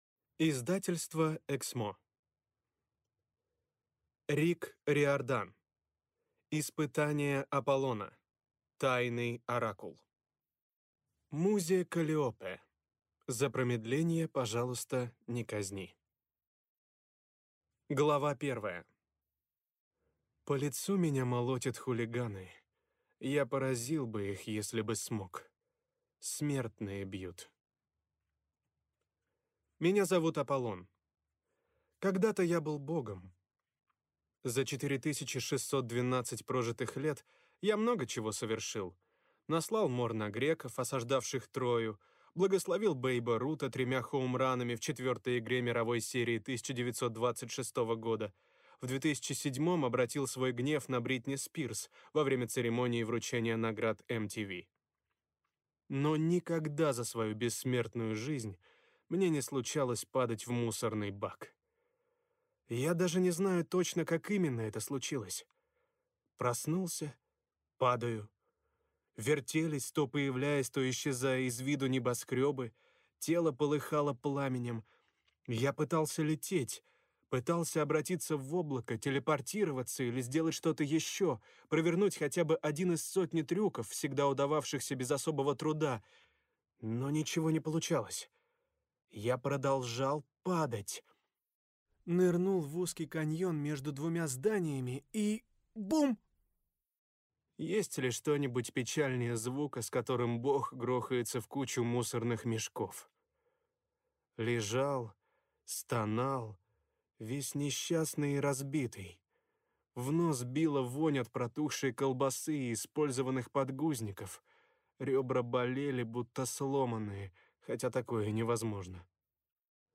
Аудиокнига Тайный оракул | Библиотека аудиокниг